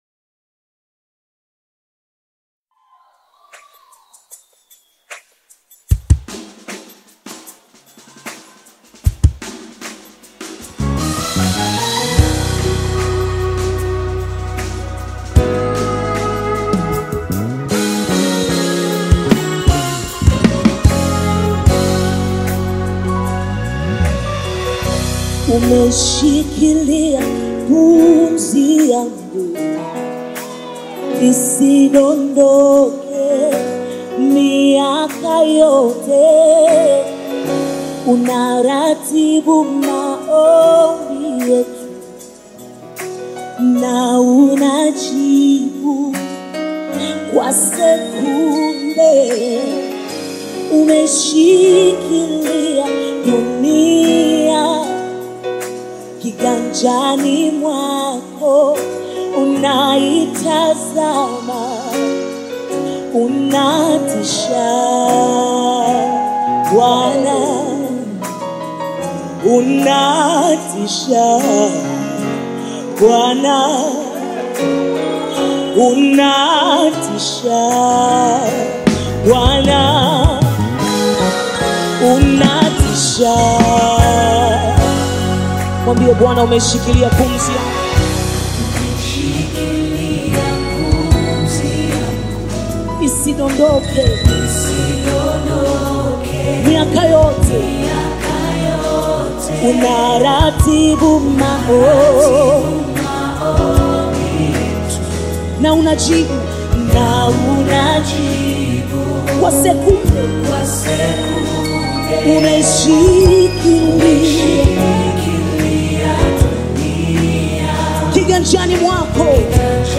Nyimbo za Dini Praise music
Praise Gospel music track